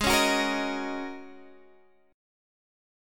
G#7sus2sus4 chord